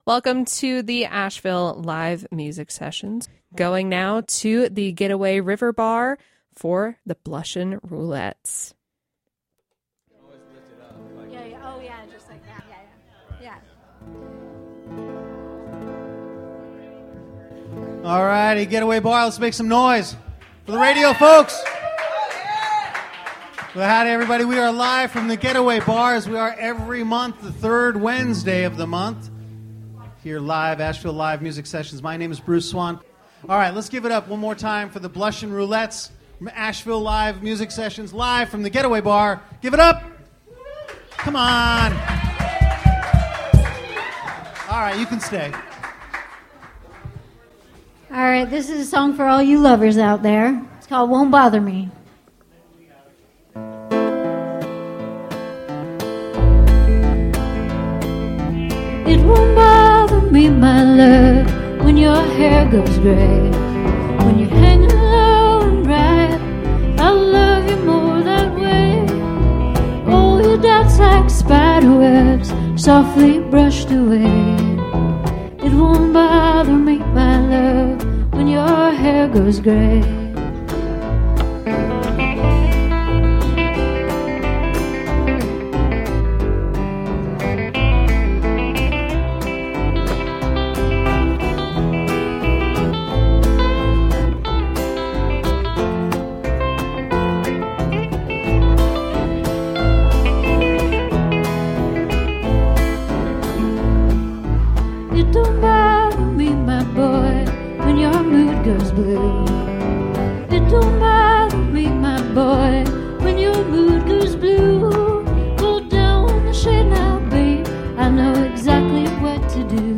Live from The Getaway River Bar